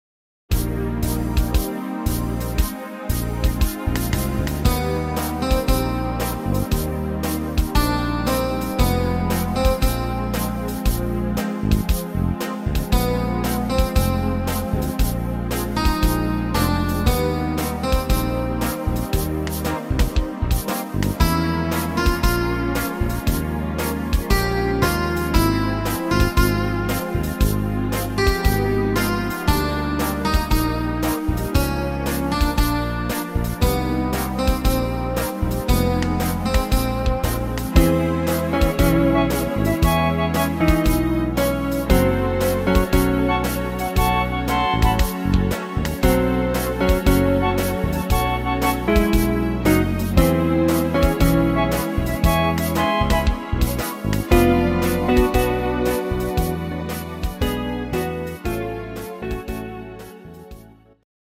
Slowfox - Latin-Standard